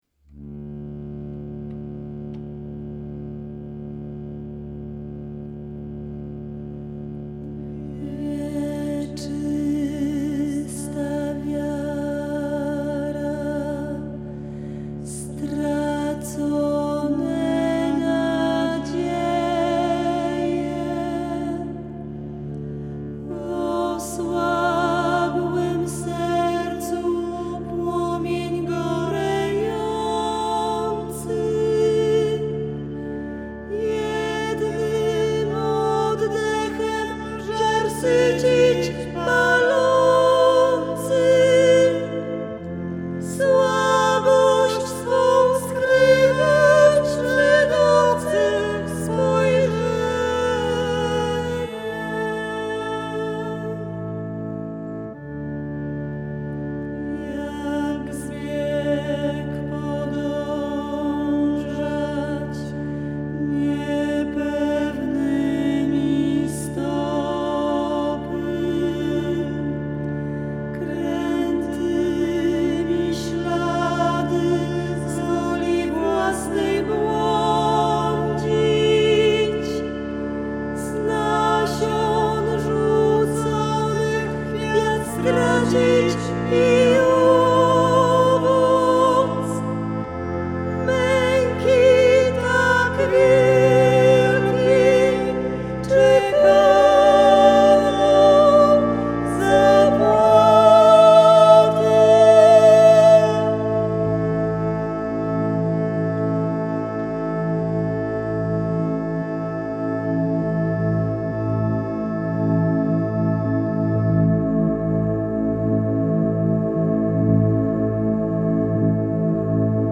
pieśń finałowa